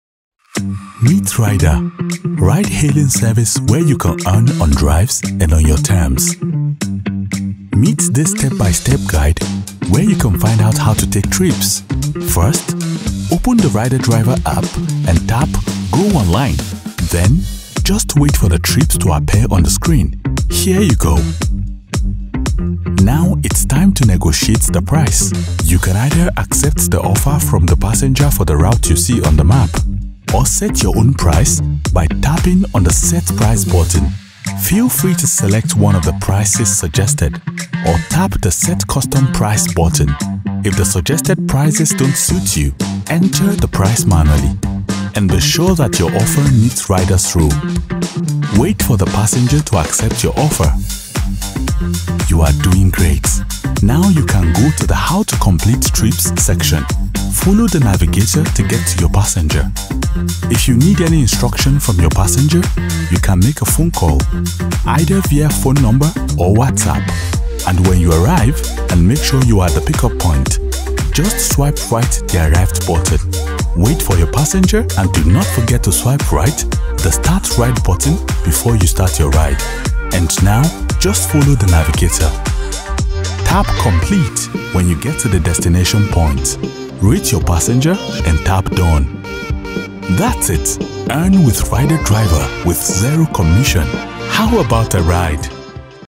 a deep, warm baritone voice with authentic African and Nigerian accents
Explainer Videos
I specialize in recording voiceovers with authentic African accents, including West African and Sub saharan African accent.
Fully soundproofed booth
BaritoneBassDeep